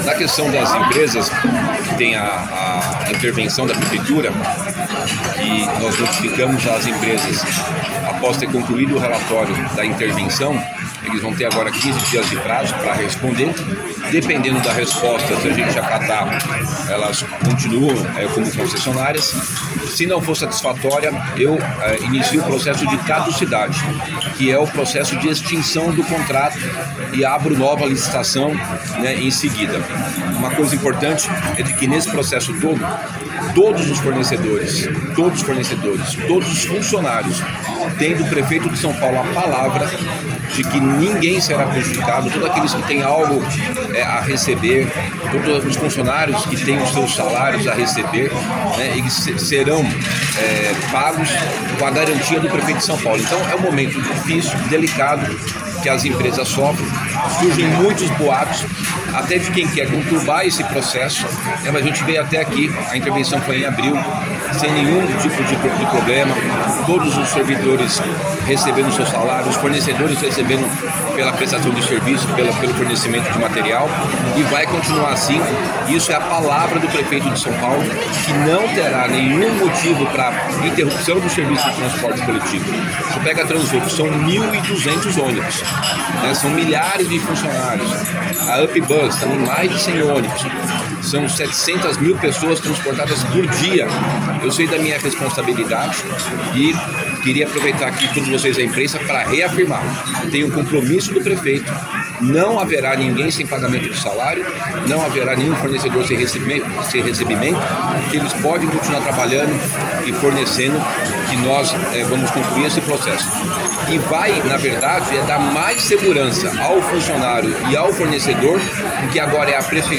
ENTREVISTA: Ricardo Nunes confirma que Transwolff e UPBus podem ter contrato rescindido e aberta nova licitação.